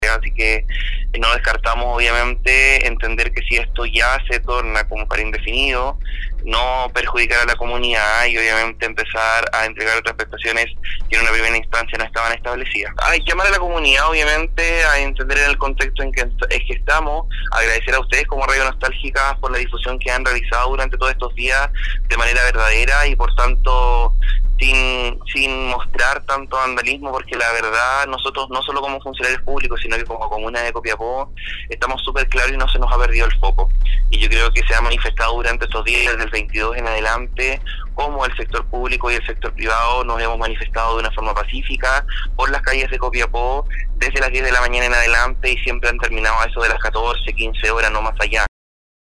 En un contacto telefónico con Nostálgica